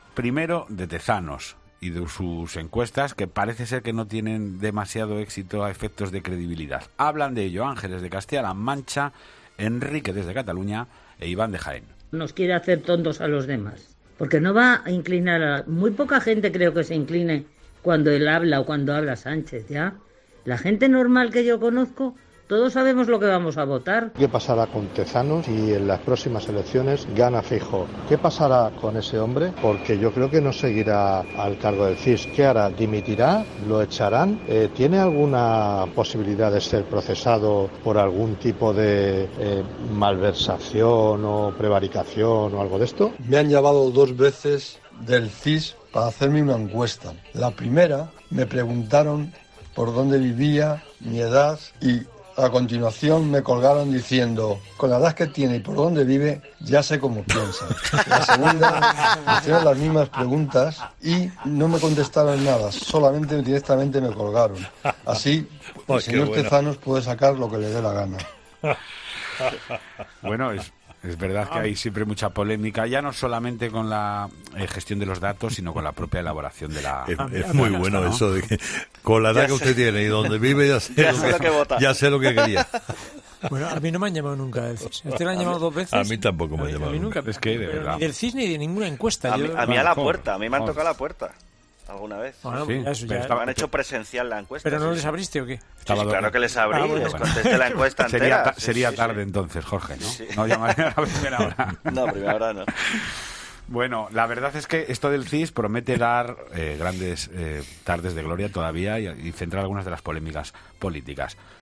Los oyentes opinan sobre el CIS